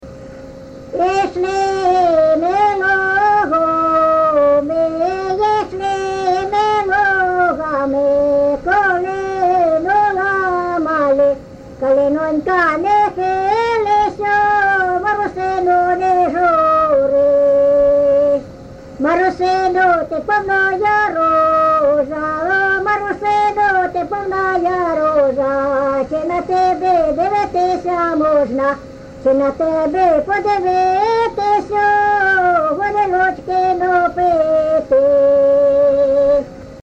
ЖанрВесільні
Місце записус. Ярмолинці, Роменський район, Сумська обл., Україна, Слобожанщина